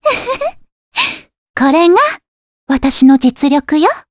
Cassandra-Vox_Victory_jp.wav